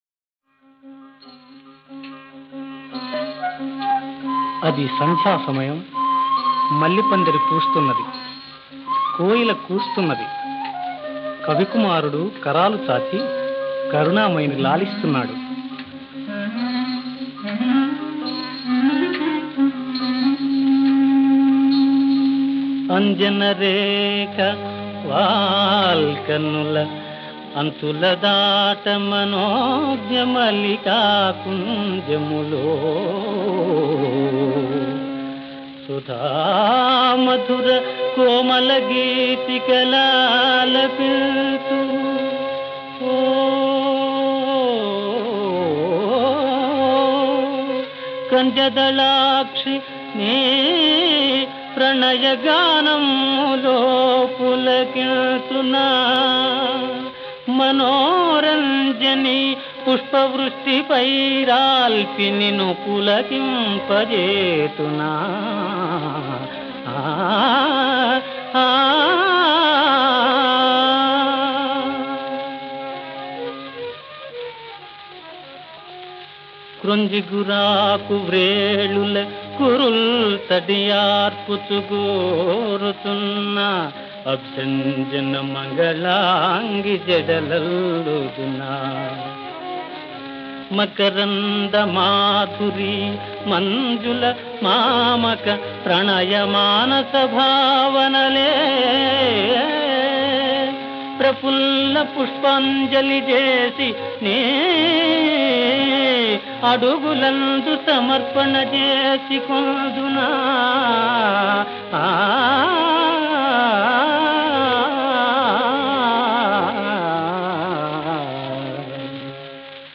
ఈ రాగం ఒక గమ్మత్తైన మత్తుని, మైకాన్ని కలిగిస్తుంది.